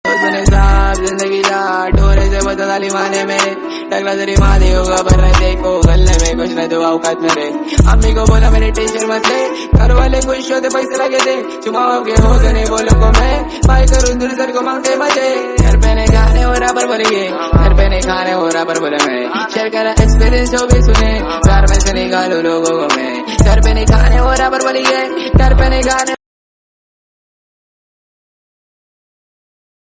hip-hop ringtone